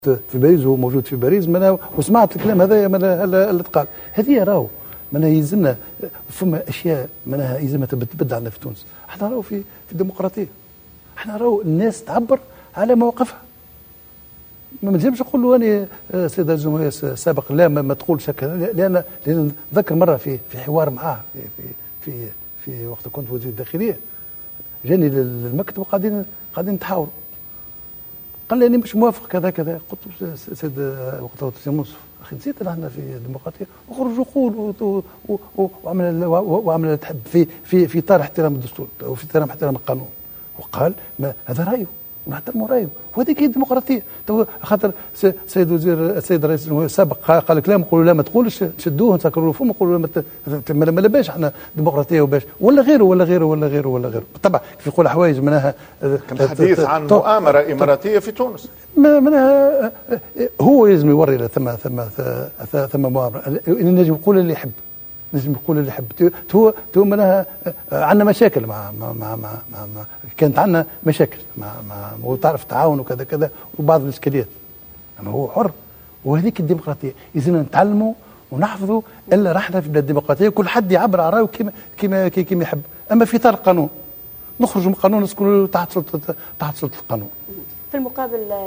واعتبر في مقابلة صحفية بثها التلفزيون الرسمي ان تونس دولة ديمقراطية ومن حق المرزوقي التعبير عن موقفه بكل حرية.